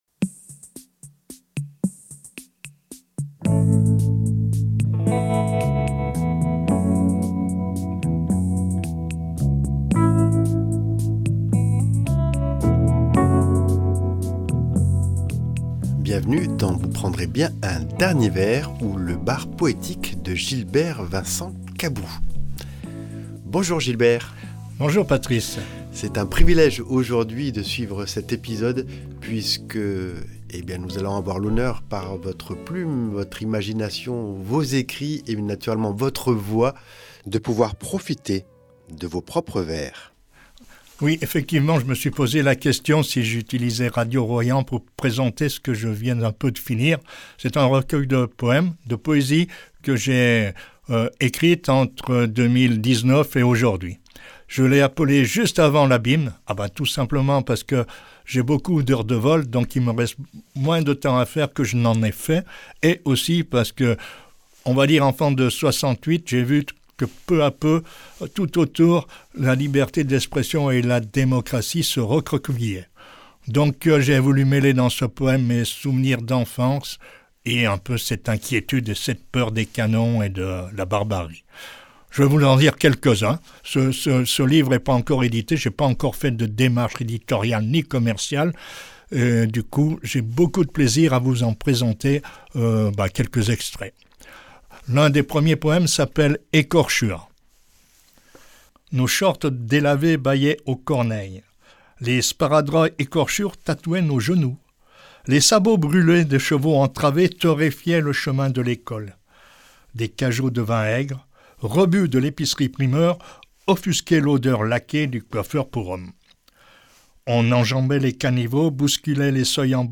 Au programme : lecture d’un poème par semaine accompagnée d’une petite biographie de l’auteur ou l’autrice. Le choix des poètes et poétesses se fait de manière totalement subjective, loin des locataires de Lagarde et Michard et souvent en prise avec l’actualité.